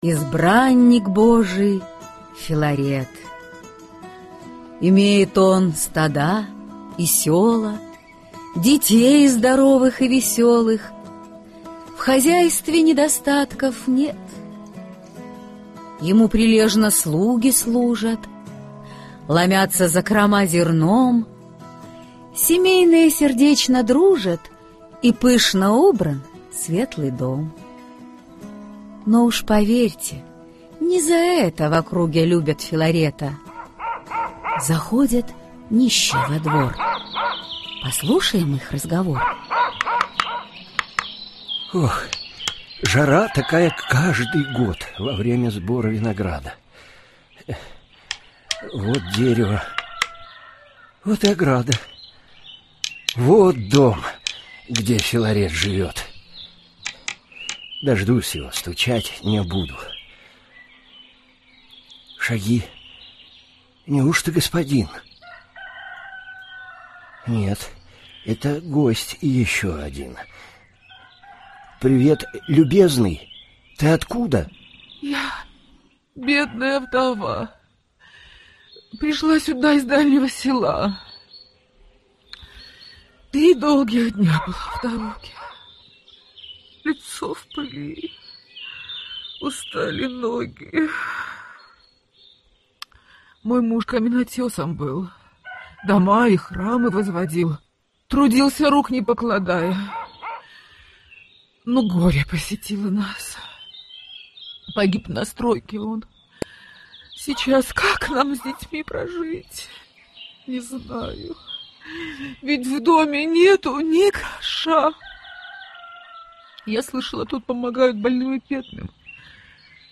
Аудиокнига Сказ о святом праведном Филарете Милостивом | Библиотека аудиокниг